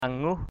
/a-ŋuh/ (d.) trục cuốn vải (khung cửi) = poitrinière (métier à tisser). breast beam (loom).